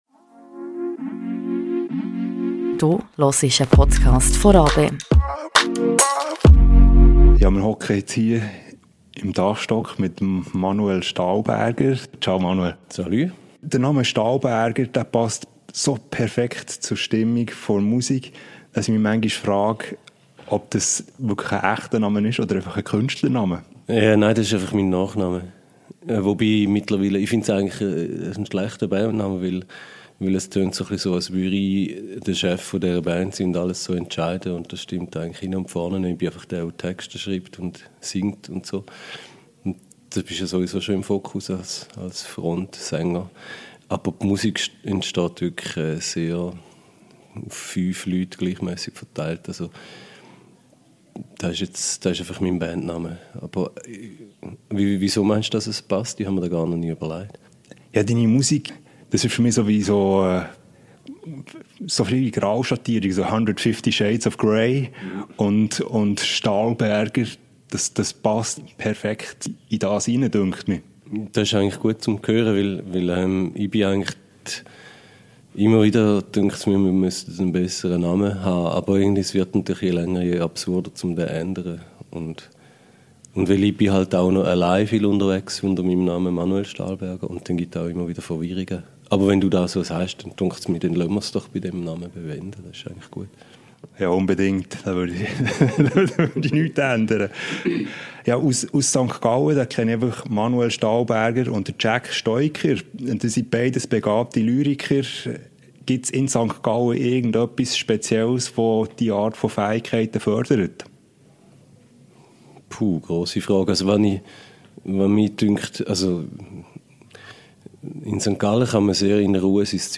Amplifier-Interview mit Manuel Stahlberger ~ Radio RaBe Podcast